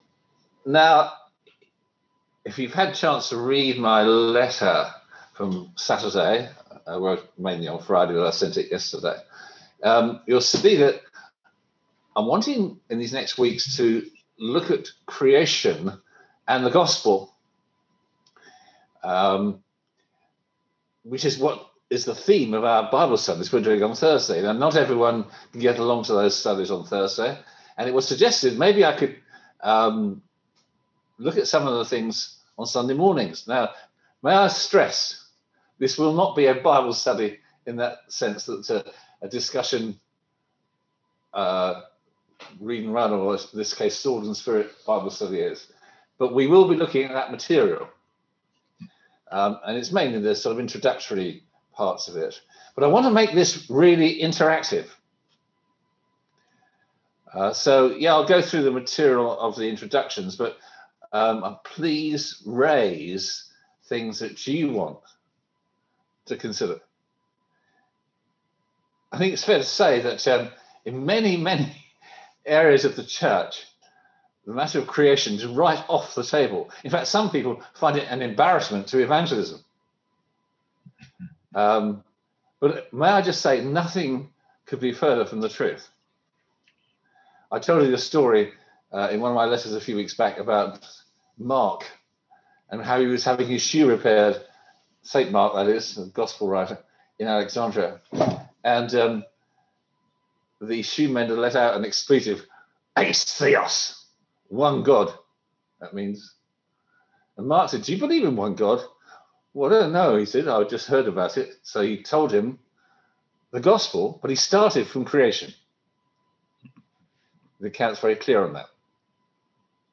Service Type: On-Line Sunday Service (English)